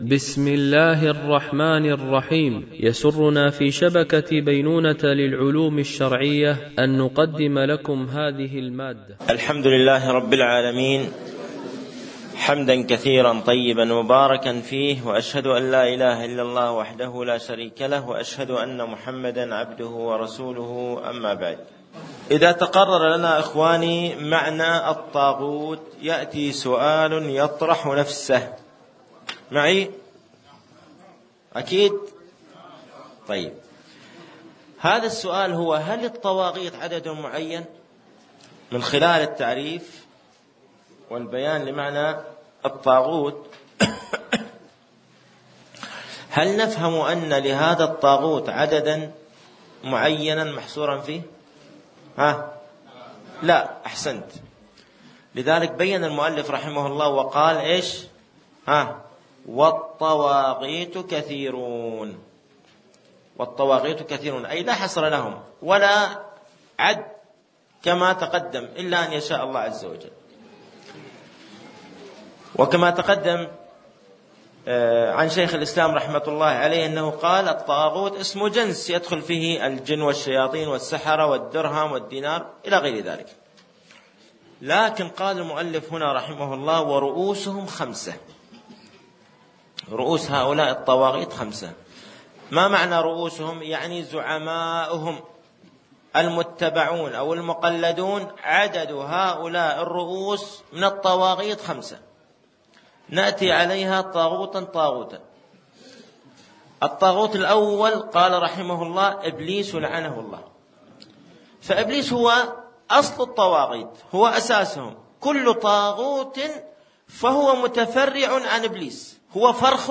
الدرس 14
MP3 Mono 44kHz 96Kbps (VBR)